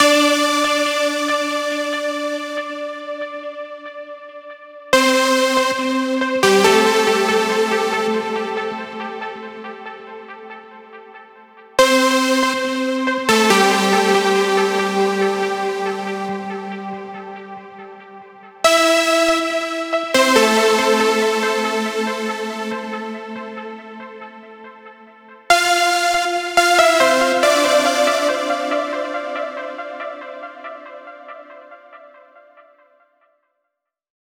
VTS1 25 Kit 140BPM Kickroll.wav 2.9 MiB